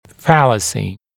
[‘fæləsɪ][‘фэлэси]ошибка, заблуждение, ошибочность, ложный вывод